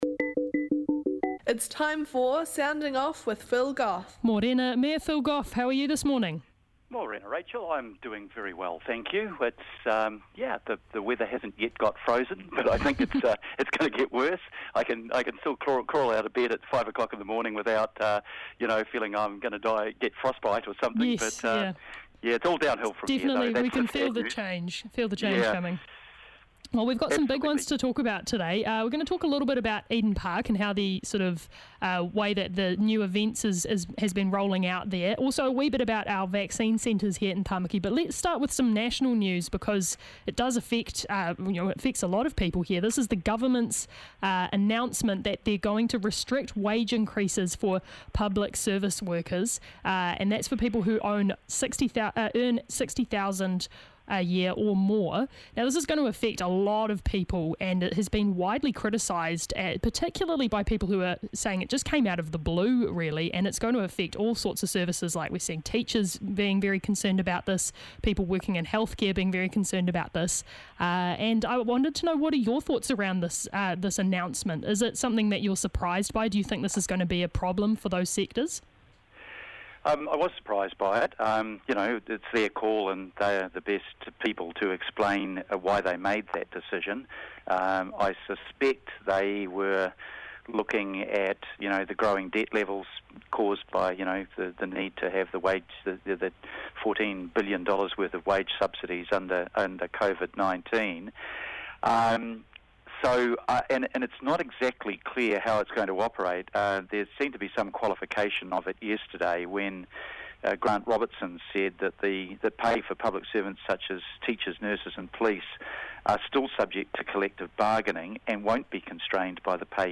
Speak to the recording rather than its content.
The Mayor is on the line and i tēnei ata he's talking about the wage freeze in the public sector, the future of events at Eden Park; and new vaccine centres in Auckland.